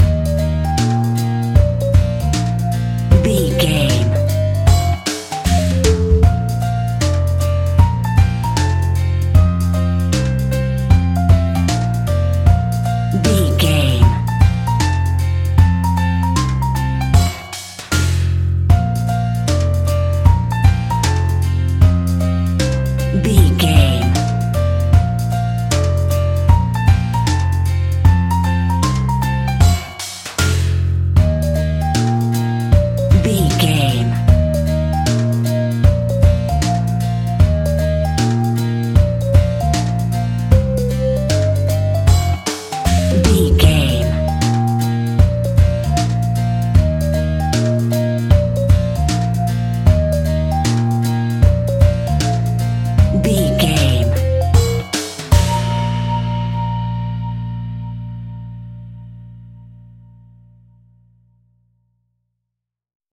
A great piece of royalty free music
Ionian/Major
B♭
childrens music
childlike
happy
kids piano